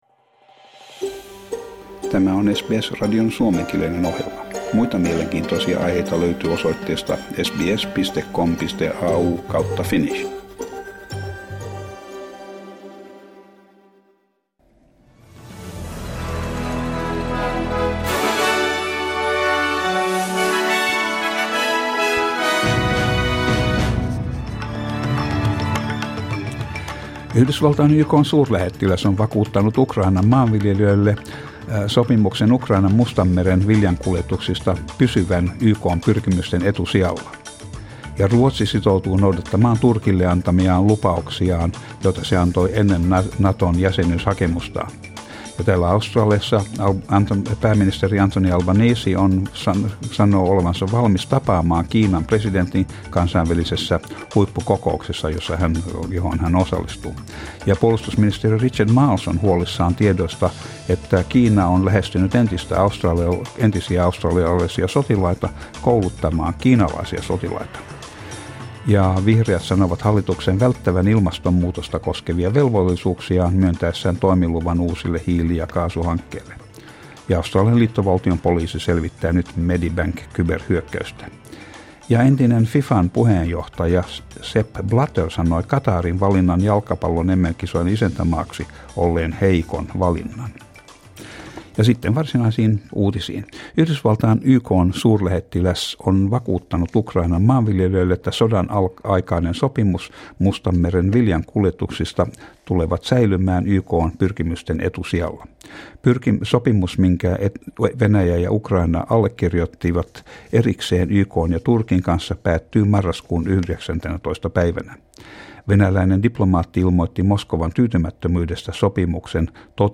Suomenkieliset uutiset Source: SBS